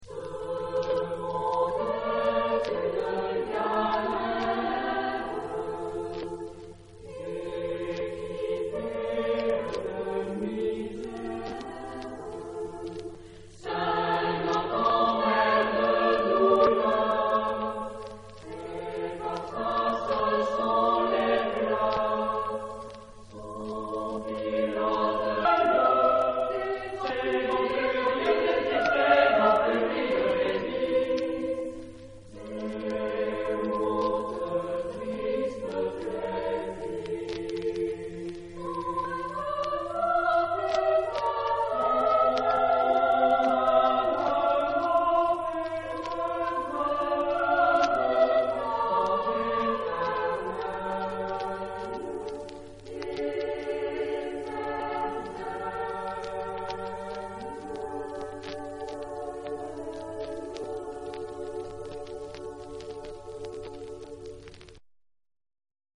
Época : Siglo 16
Género/Estilo/Forma: Renacimiento ; Profano
Tipo de formación coral: SAH O SAT  (3 voces Coro mixto )